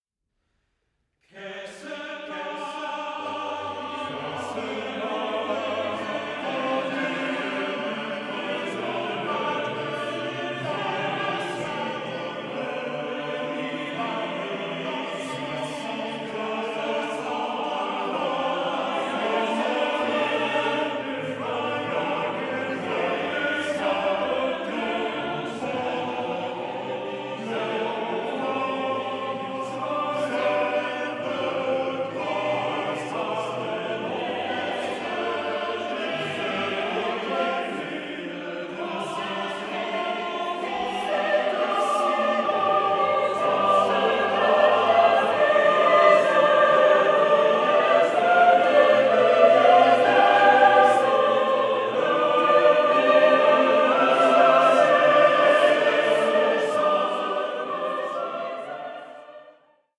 SACRED CHORAL MASTERWORKS